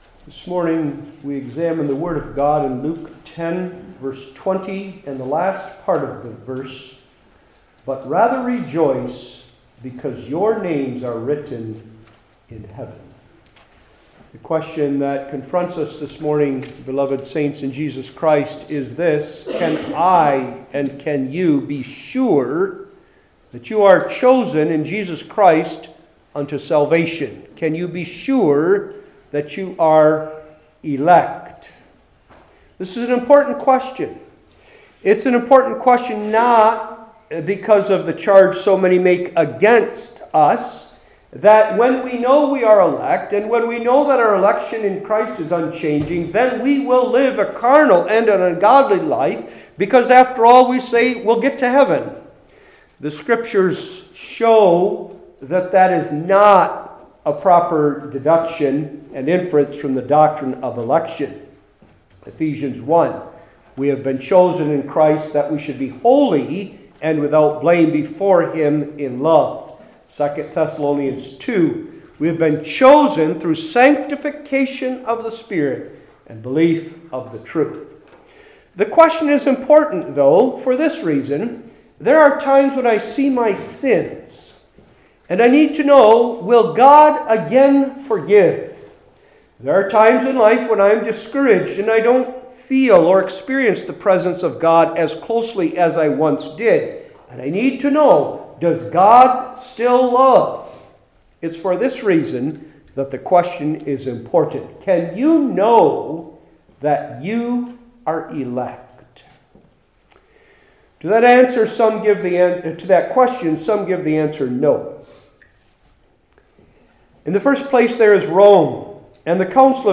New Testament Individual Sermons I. Our Election II.